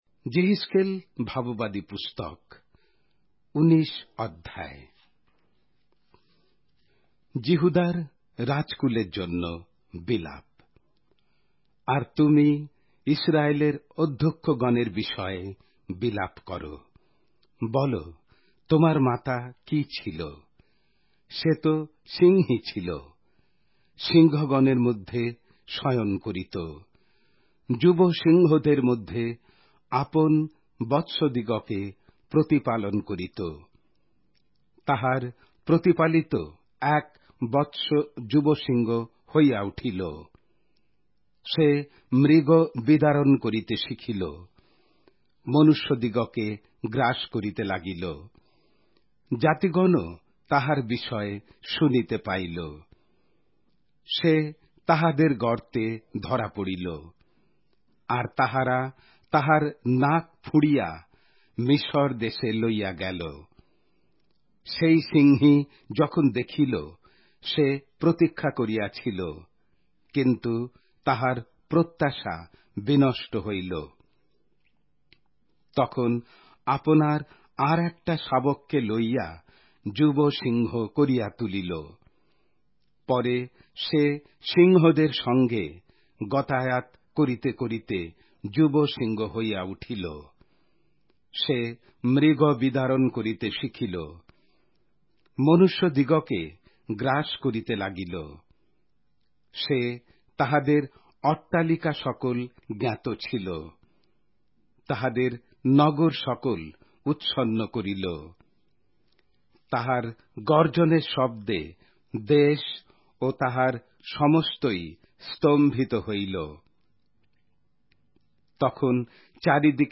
Bengali Audio Bible - Ezekiel 4 in Tov bible version